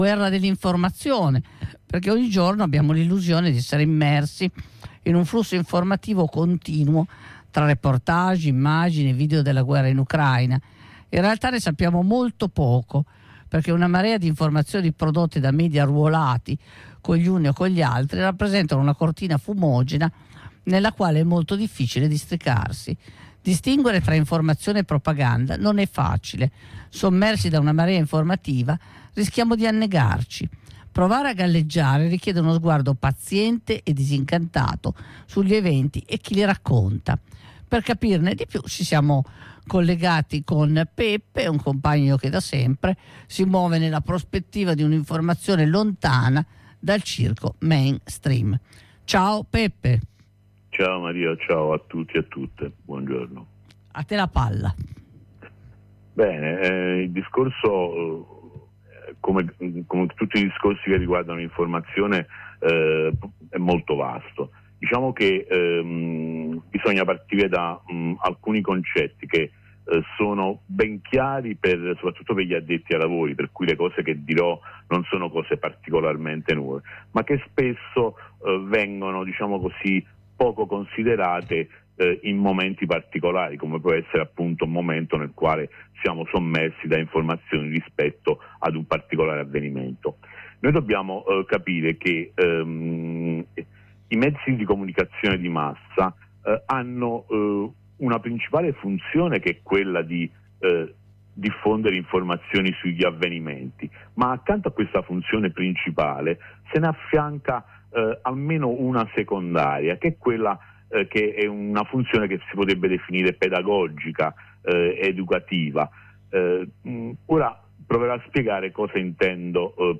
Ascolta la diretta: